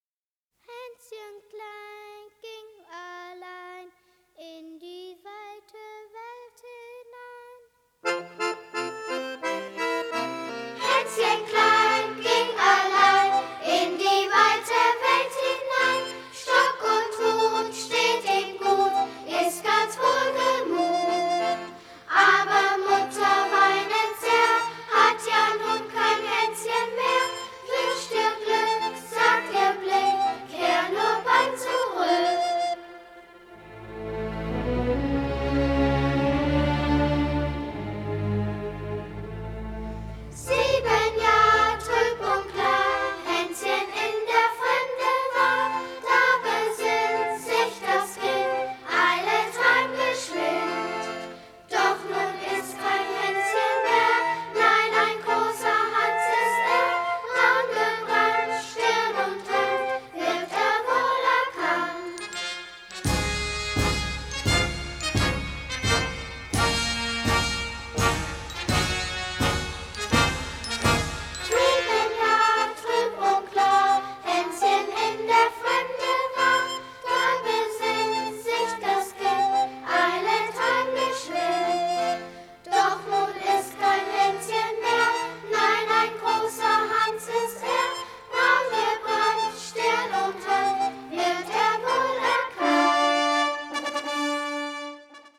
features a children’s choir singing the traditional song